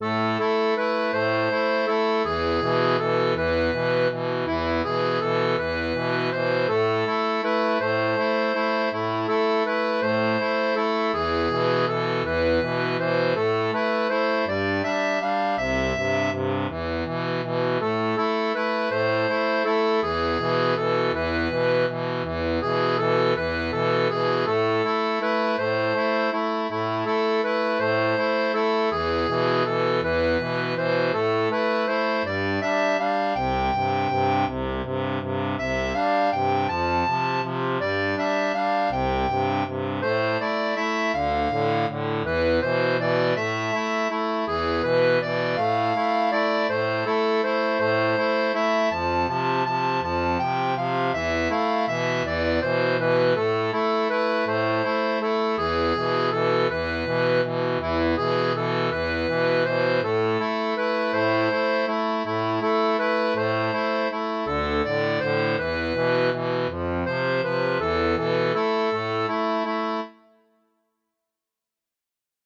Type d'accordéon
Chanson française